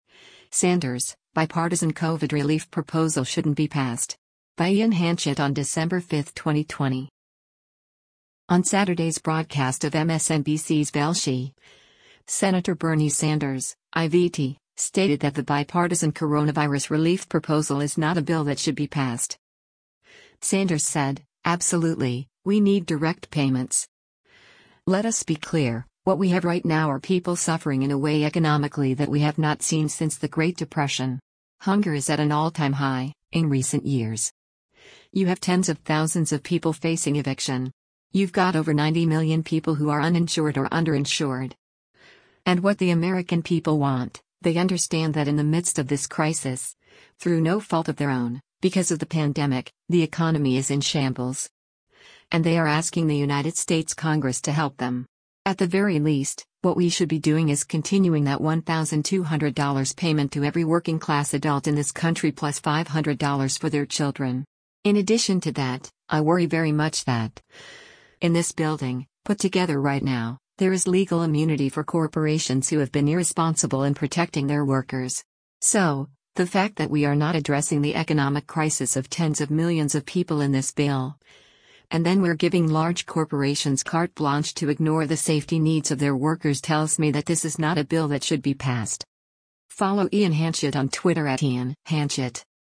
On Saturday’s broadcast of MSNBC’s “Velshi,” Sen. Bernie Sanders (I-VT) stated that the bipartisan coronavirus relief proposal “is not a bill that should be passed.”